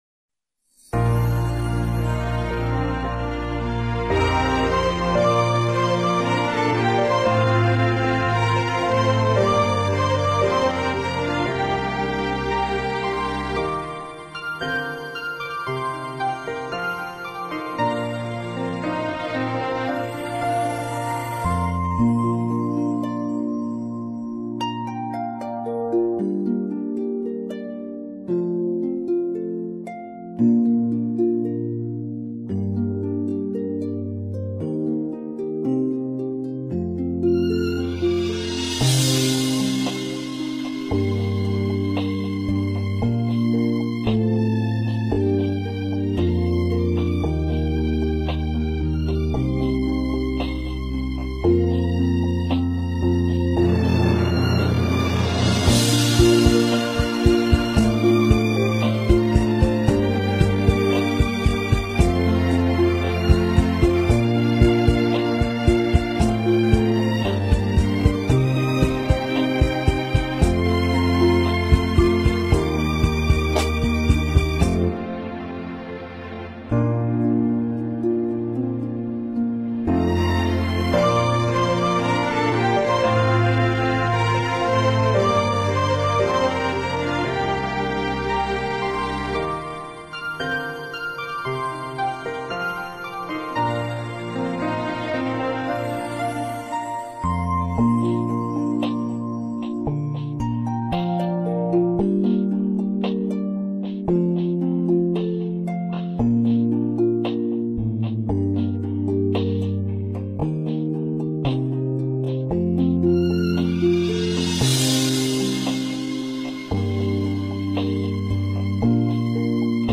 降B调伴奏